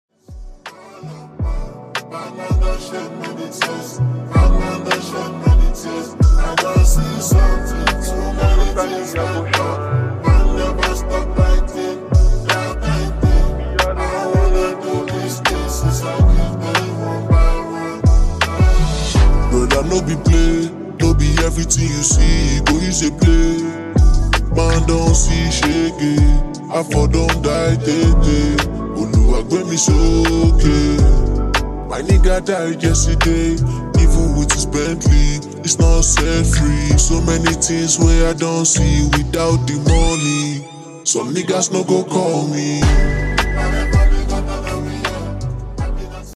Slow version for now!